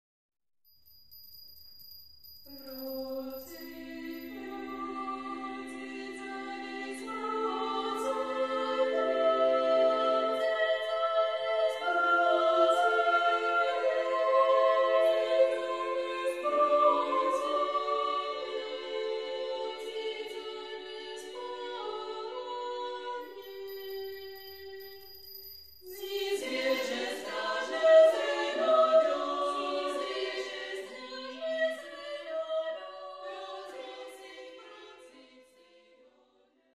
Komorní pěvecké sdružení Ambrosius
Demo nahrávka, prosinec 2000